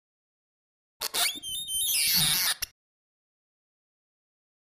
Beeps Sci-Fi Space Machine Beep 1